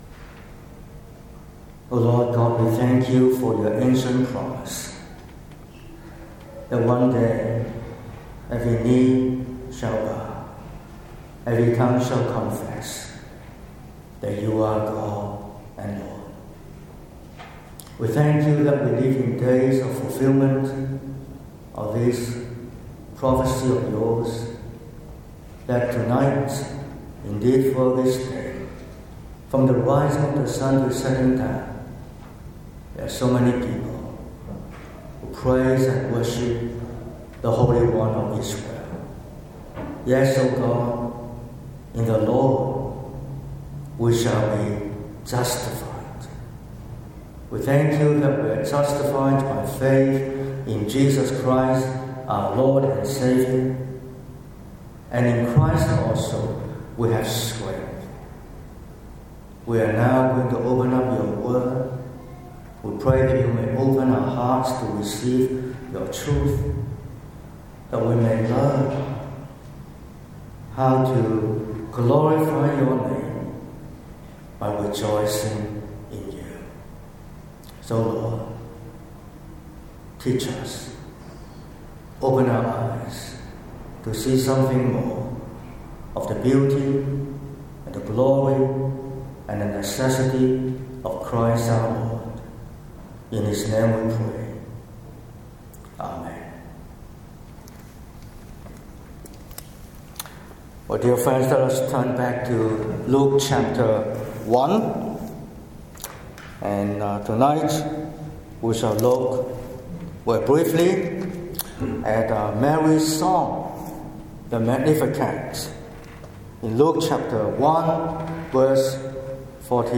30/11/2025 – Evening Service: Mary’s song – the Magnificat - Maroubra Presbyterian Church
Sermon Outline